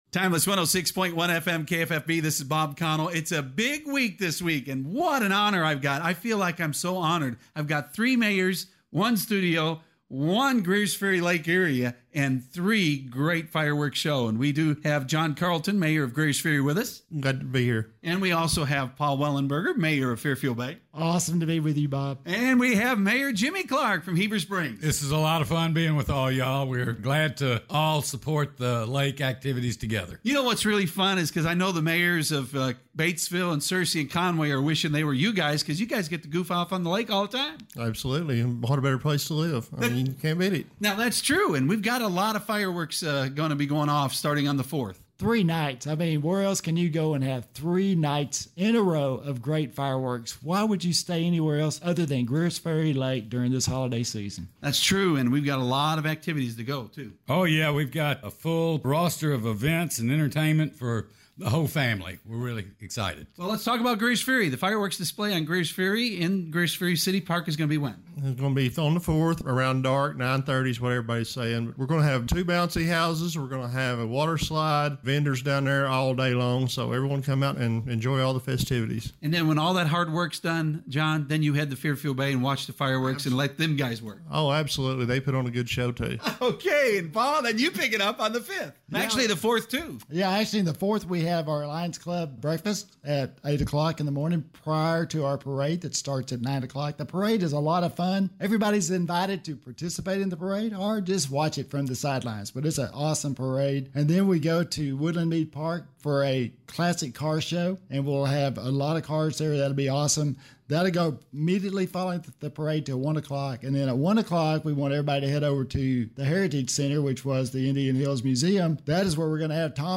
Talks on Open Mic with John Carlton Mayor of Greers Ferry, Paul Wellenberger Mayor of Fairfield Bay, and Jimmie Clark Mayor of Heber Springs!